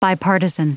Prononciation du mot bipartisan en anglais (fichier audio)
Prononciation du mot : bipartisan